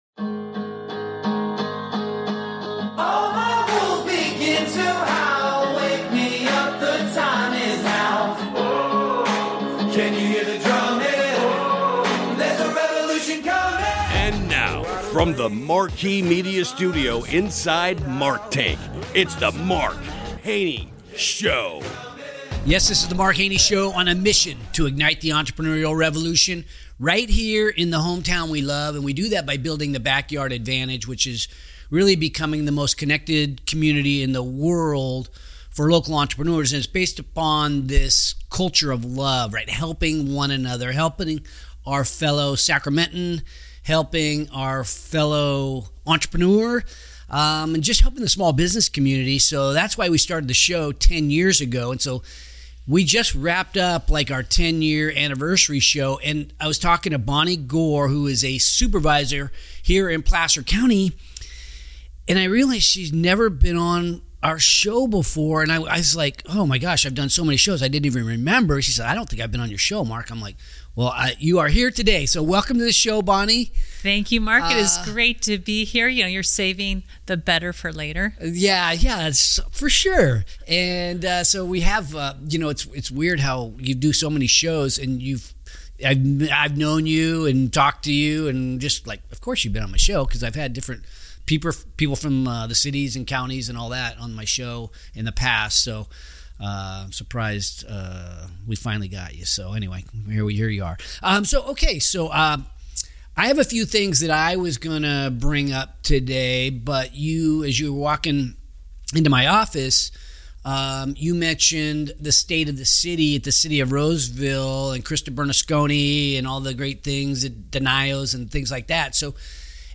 In this episode, Supervisor Bonnie Gore breaks down what’s fueling that momentum: strong local businesses, great schools, strategic leadership, and a community that actually shows up for one another. This conversation dives deep into what makes this region a magnet for entrepreneurs, families, and builders who want to be part of something bigger.